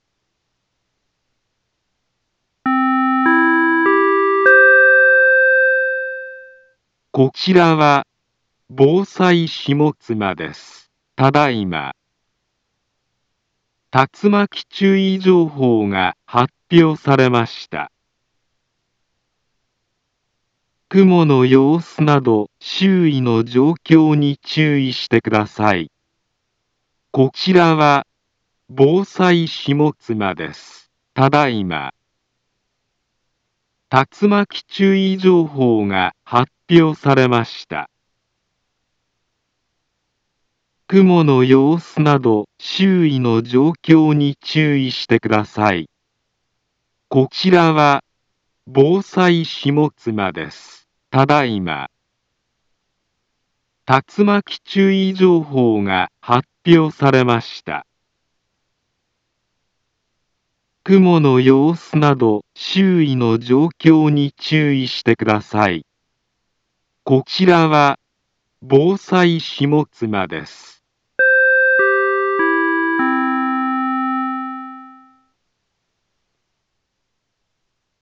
Back Home Ｊアラート情報 音声放送 再生 災害情報 カテゴリ：J-ALERT 登録日時：2024-09-18 14:34:32 インフォメーション：茨城県北部、南部は、竜巻などの激しい突風が発生しやすい気象状況になっています。